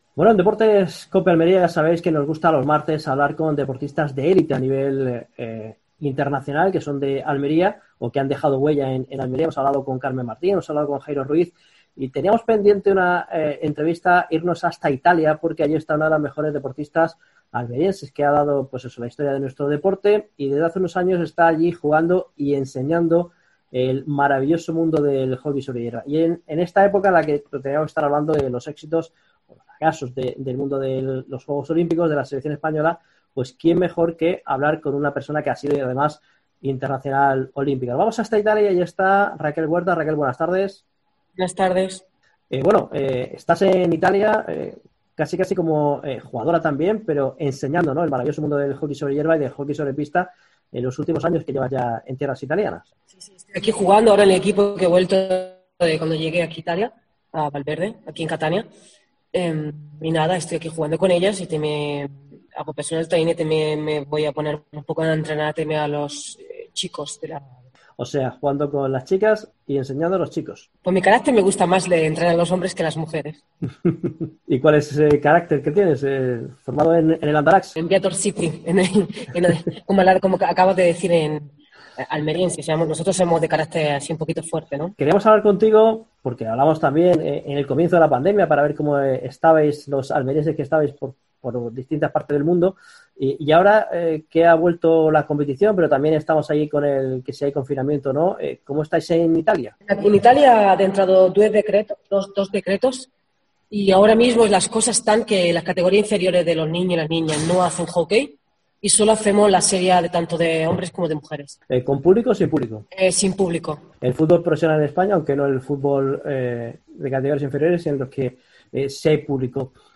Entrevista en Deportes COPE Almería.